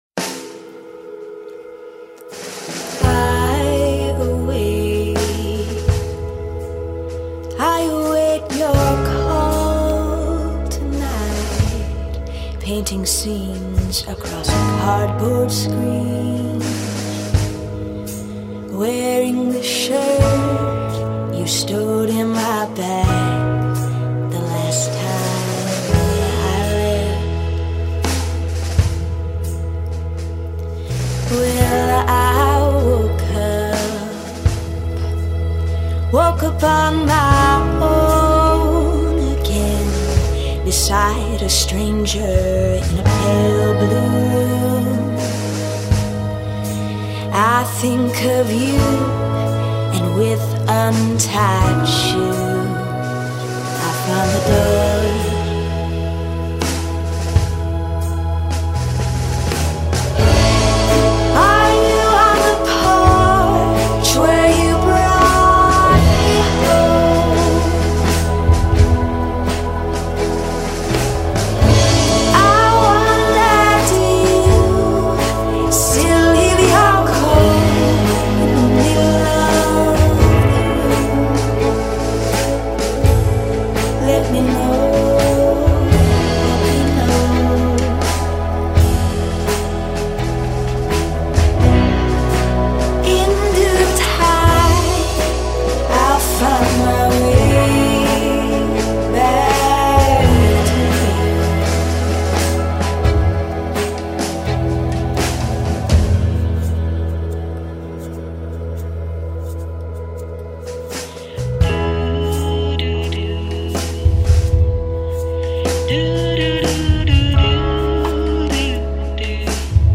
indie-folk flavored
downtempo gem